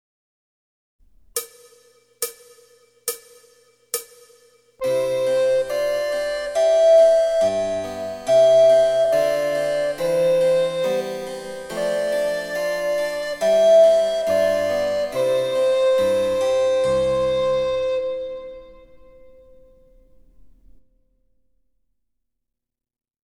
リコーダー演奏